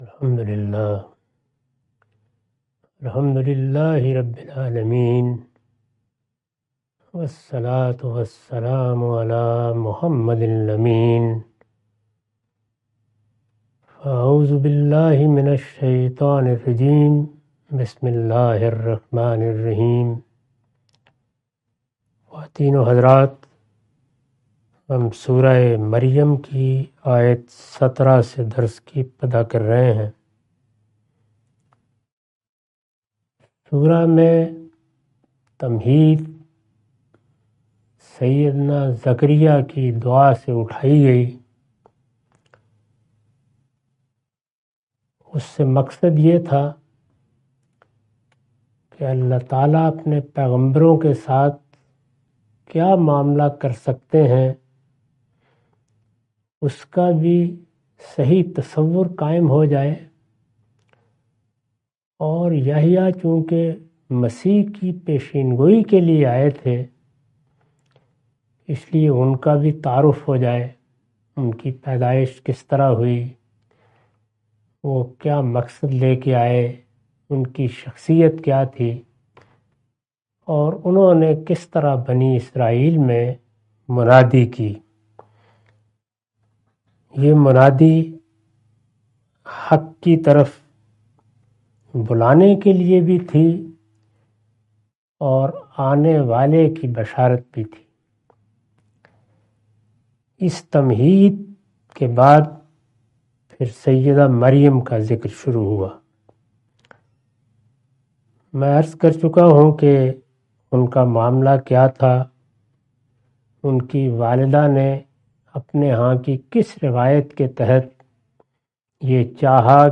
Surah Maryam A lecture of Tafseer-ul-Quran – Al-Bayan by Javed Ahmad Ghamidi. Commentary and explanation of verses 17-22.